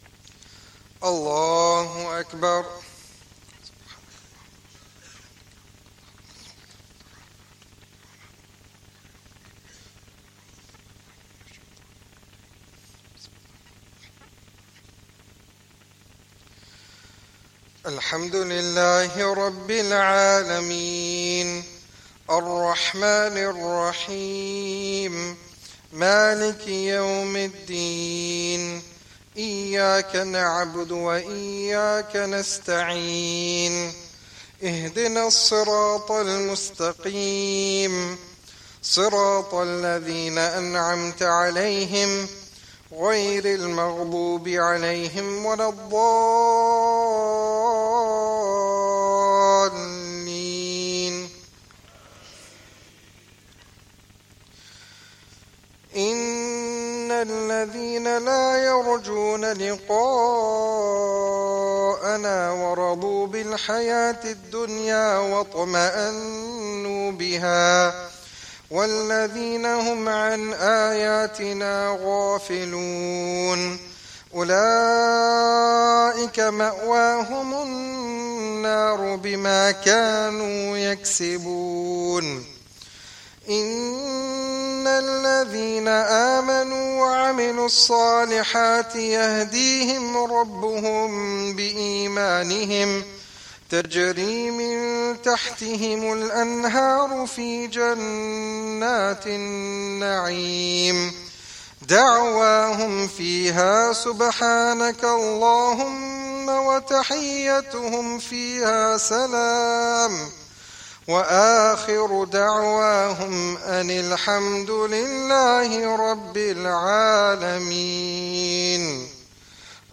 Esha Jammat